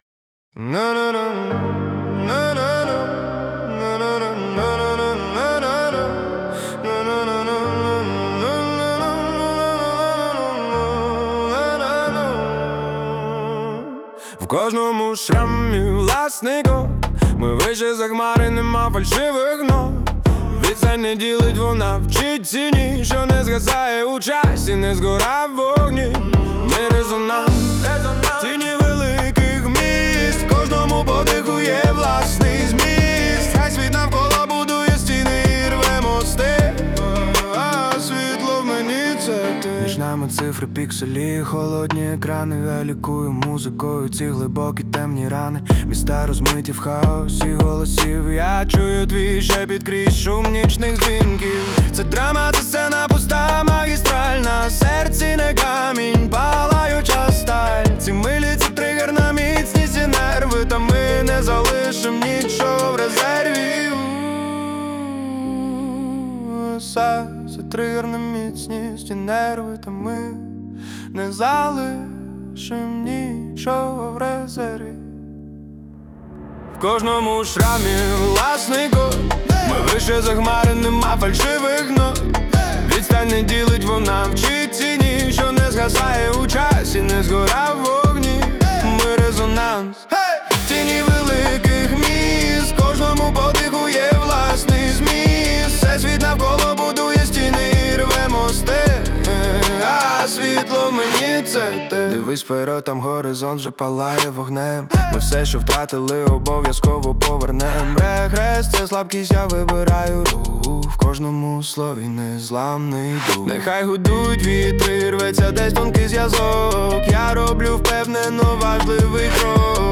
Динамічний поп-реп Стильний трек про сучасні реалії